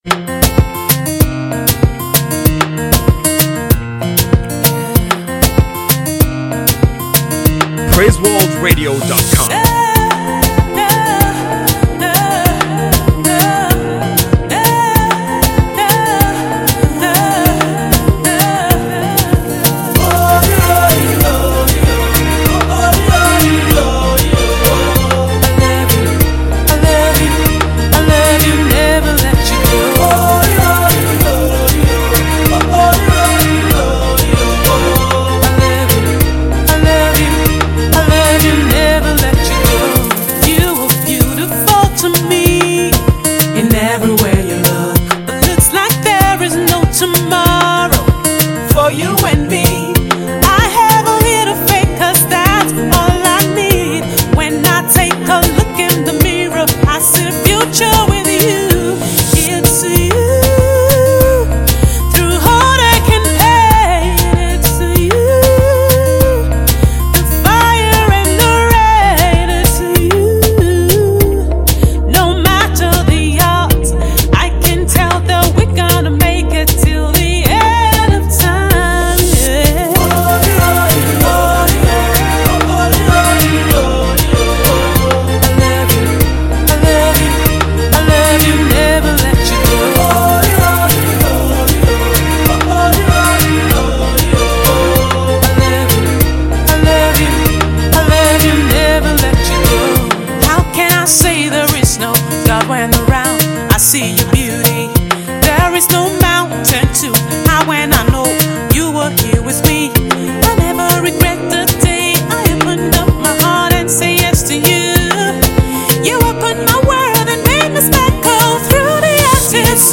pure African gospel tune